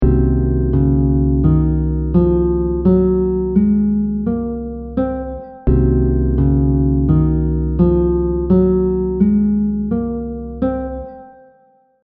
The audio examples will repeat the scale over a chord so you can get a better grasp of the sound of each mode.
• Mood / emotion: unstable, uncertain 😐
• Characteristic notes: flat 2, flat 5
C Locrian scale audio example